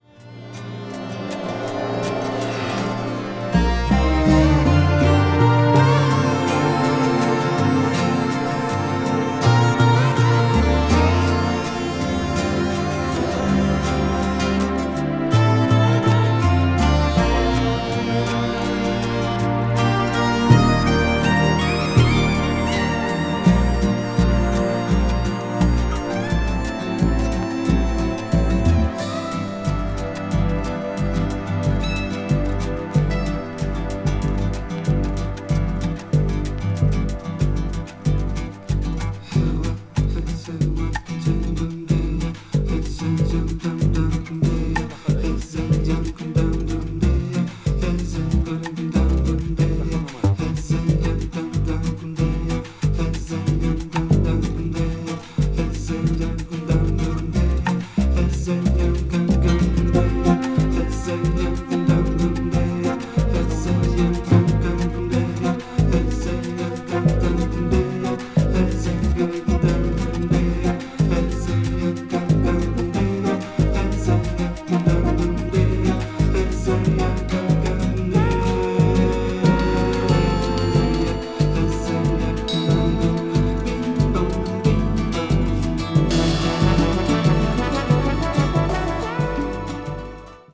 フローティングなパッド・サウンドにオリエンタルな旋律とウルグアイの情緒が交錯するA6がなかでも最高です。
crossover   ethnic jazz   fusion   jazz groove   latin jazz